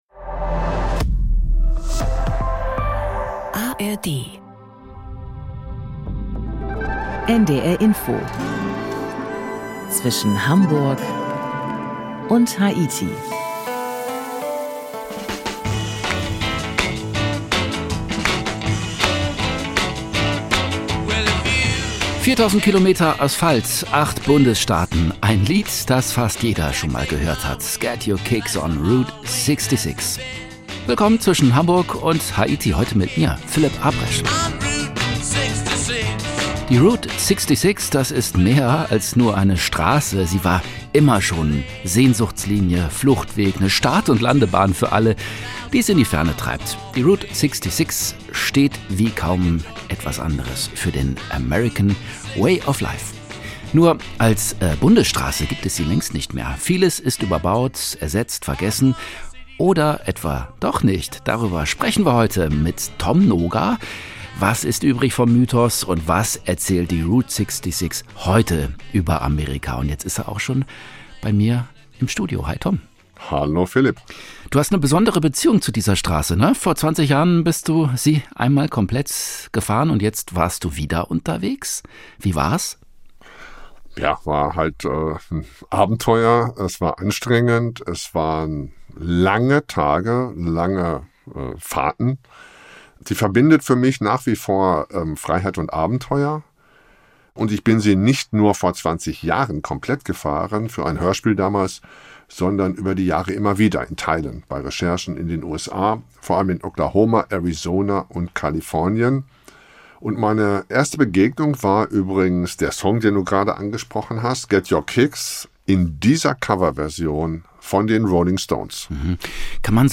Historiker, Anwohner und indigene Stimmen zeichnen ein vielschichtiges Bild jenseits des nostalgischen Mythos.